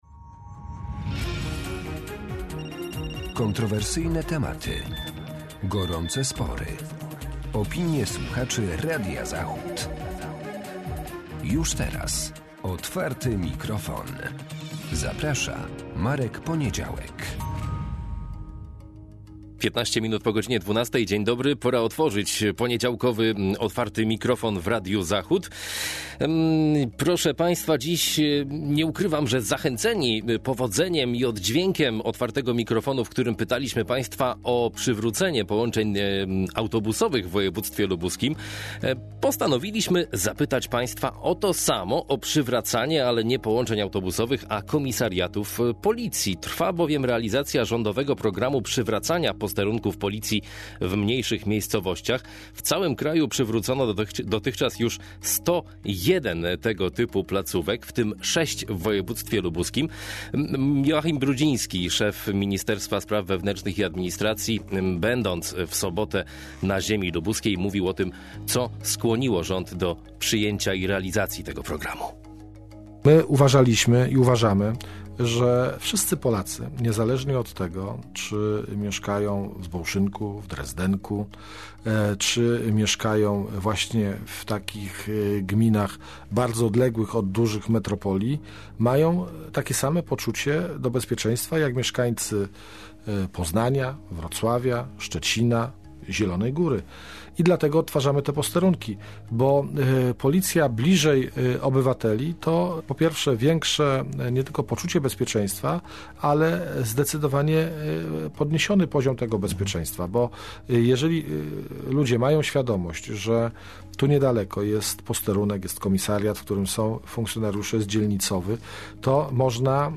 W dzisiejszym Otwartym Mikrofonie pytamy słuchaczy, gdzie powinny powrócić lub pojawić się nowe posterunki policji?